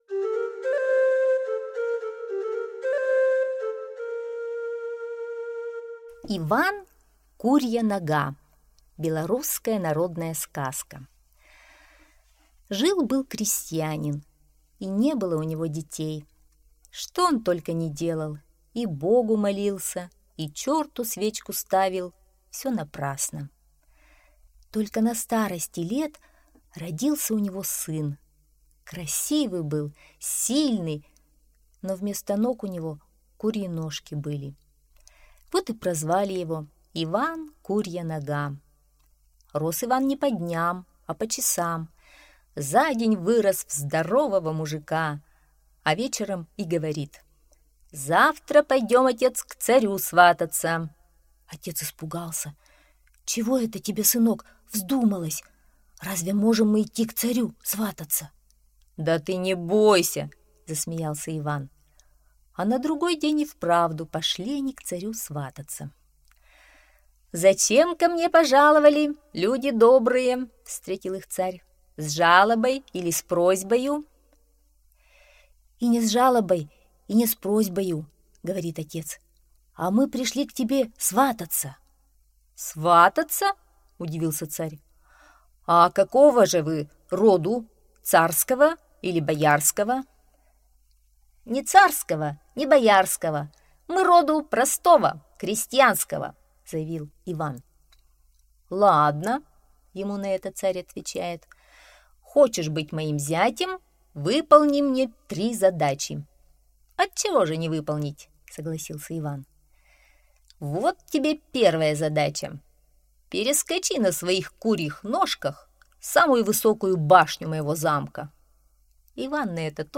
Иван-курья нога – белорусская аудиосказка